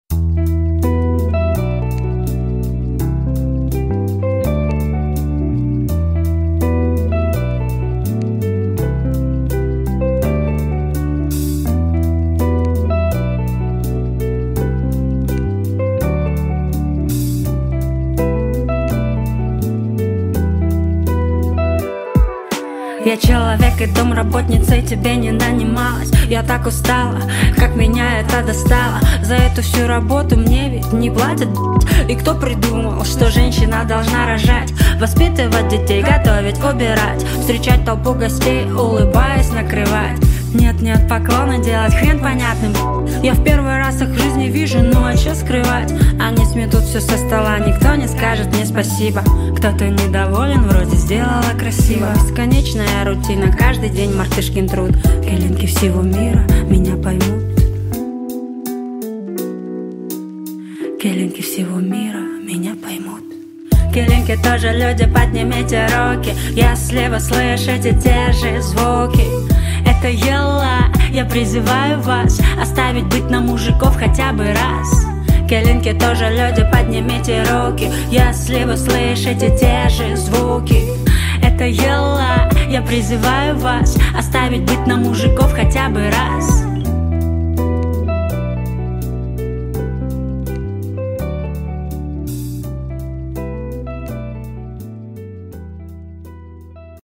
Кыргызские песни